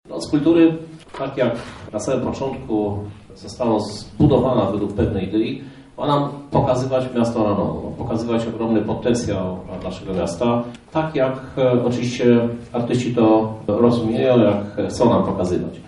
• Ważna jest umiejętność budowania współpracy, to dzięki niej tak duży projekt jest spójny – mówi prezydent miasta Lublin Krzysztof Żuk